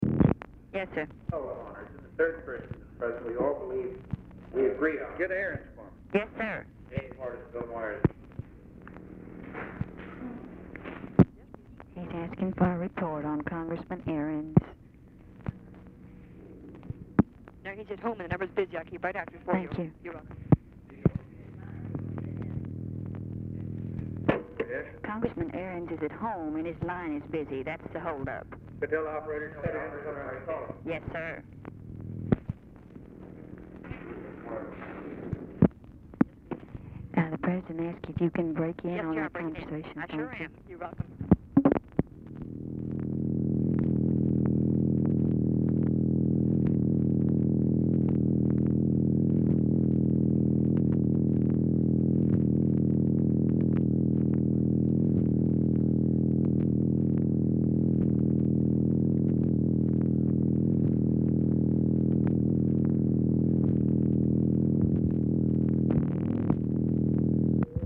Telephone conversation
BRIEF OFFICE CONVERSATION IS AUDIBLE AT BEGINNING OF CALL
Format Dictation belt
Location Of Speaker 1 Oval Office or unknown location
Other Speaker(s) TELEPHONE OPERATOR, OFFICE CONVERSATION
Speaker 2 OFFICE SECRETARY